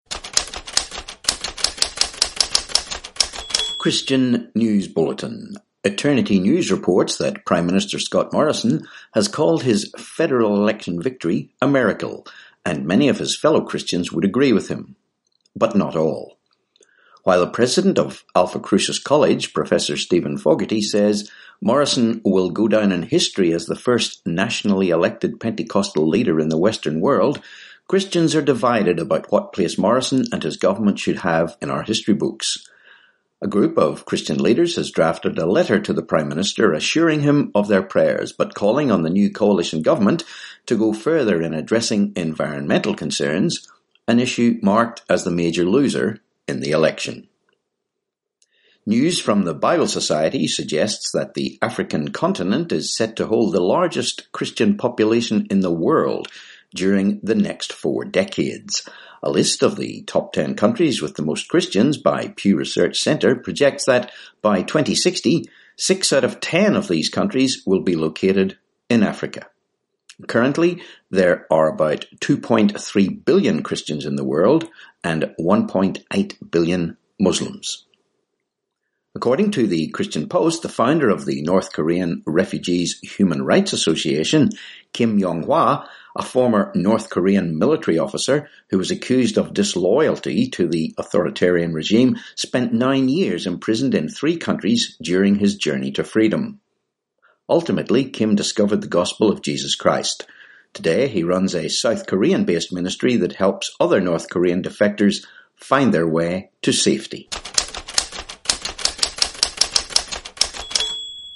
2Jun19 Christian News Bulletin